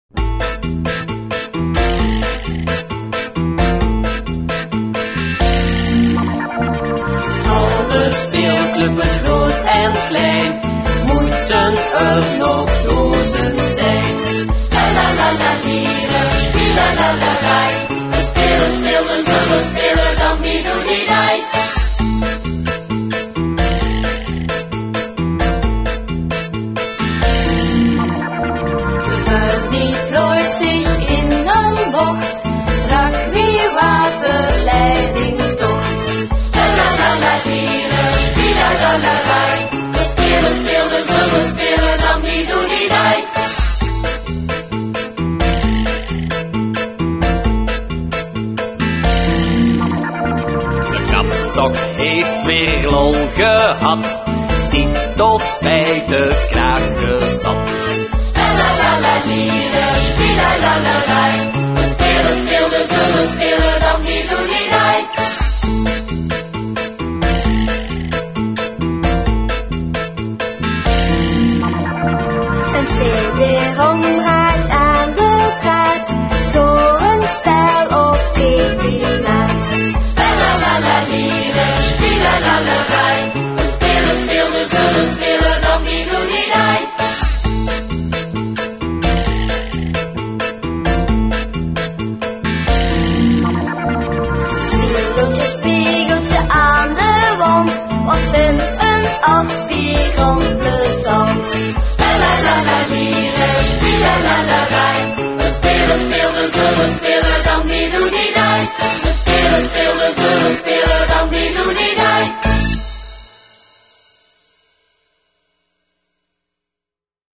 gezongen of